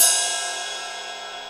DJP_PERC_ (11).wav